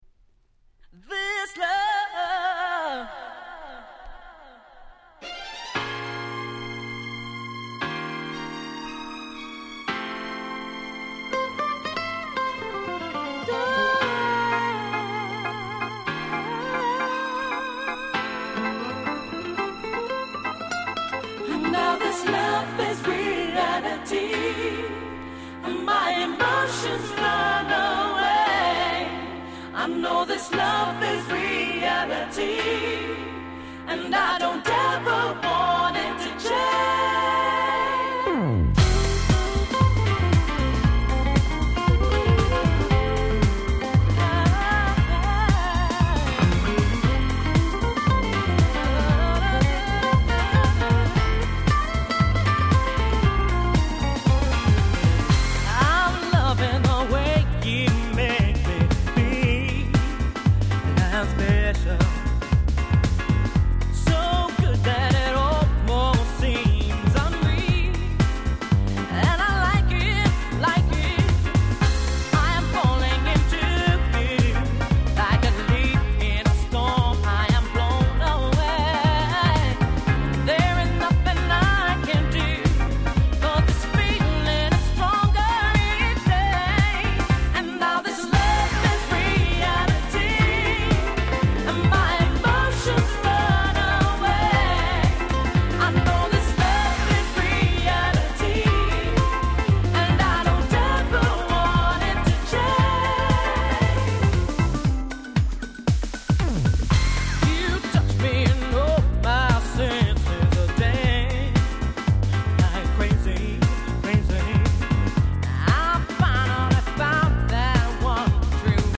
99' Nice Japanese R&B !!
フロア映えもバッチリ、速目のBPMでグイグイ盛り上げます！！